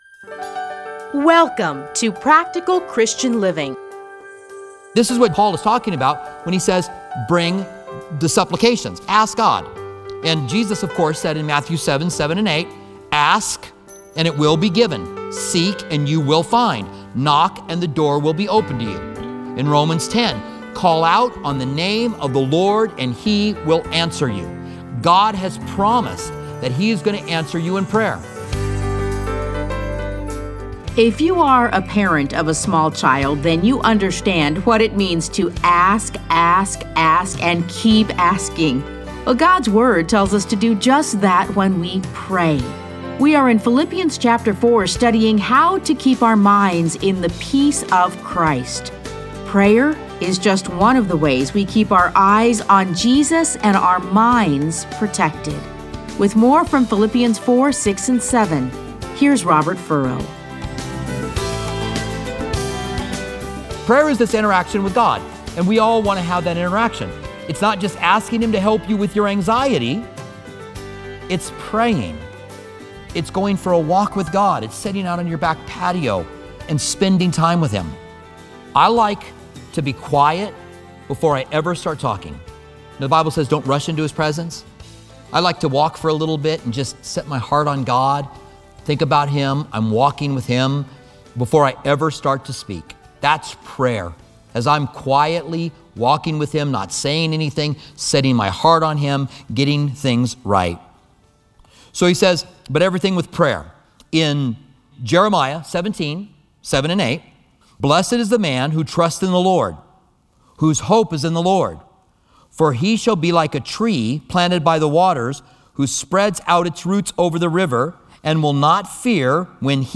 Listen to a teaching from A Study in Philippians 4:6-7.